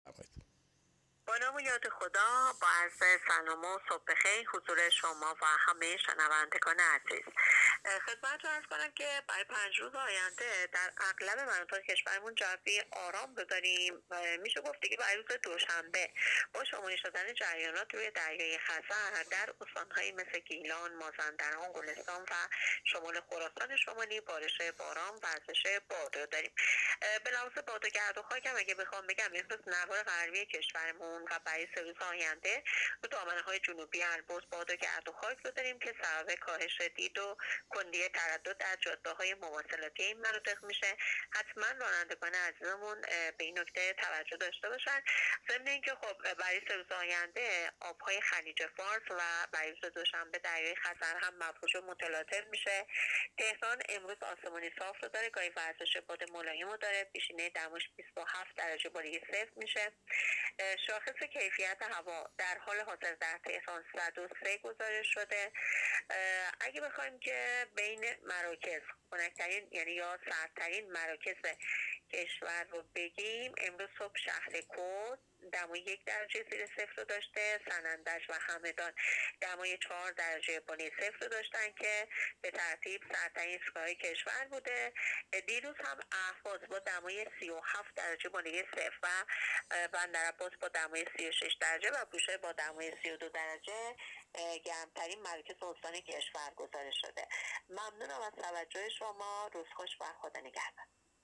گزارش رادیو اینترنتی پایگاه‌ خبری از آخرین وضعیت آب‌وهوای دوم آبان؛